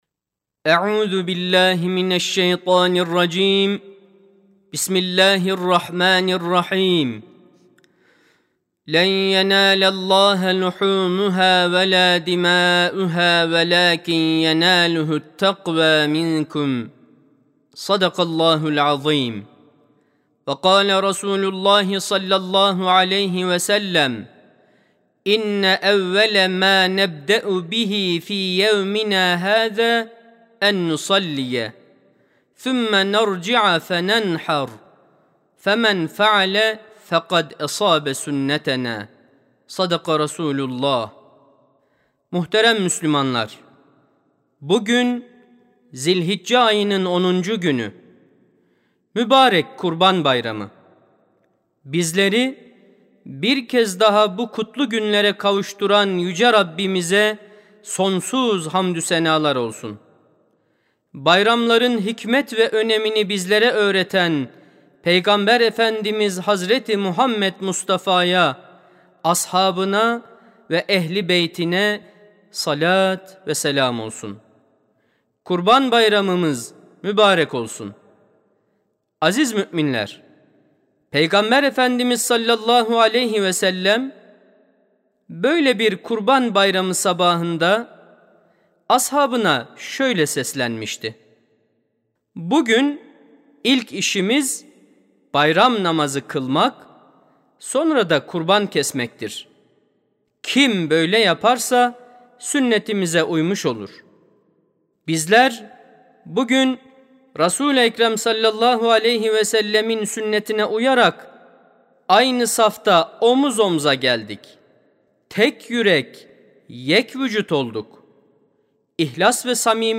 06.06.2025 Kurban Bayramı Hutbesi: Kurban Bayramı, Allah’ın Kullarına İhsan ve İkramı (Sesli Hutbe, Türkçe, İngilizce, Rusça, İspanyolca, Almanca, İtalyanca, Arapça, Fransızca)
Sesli Hutbe (Kurban Bayramı, Allah’ın Kullarına İhsan ve İkramı).mp3